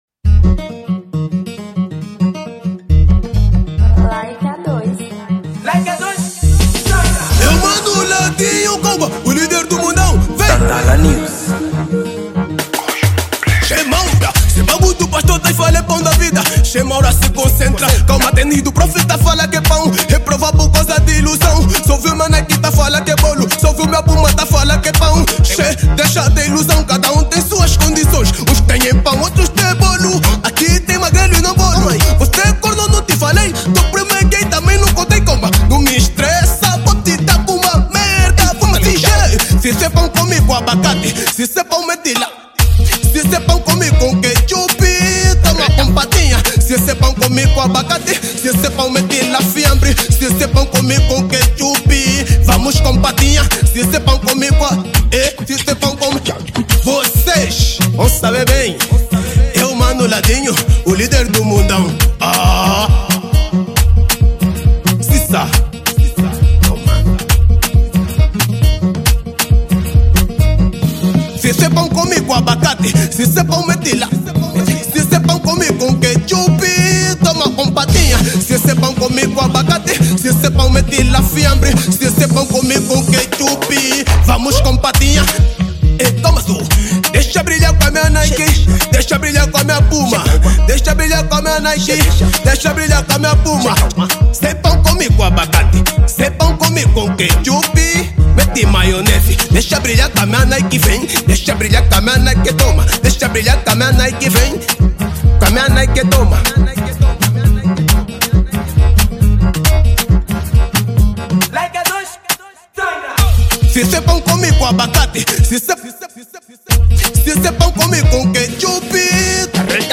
Gênero: Afro House